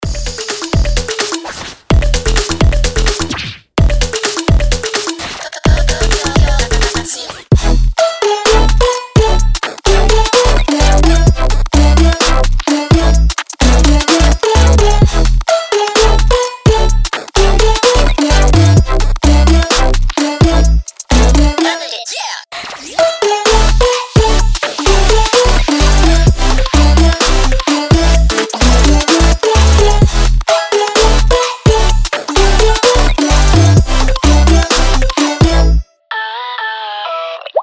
веселые
саундтреки
Electronica
Breaks
breakbeat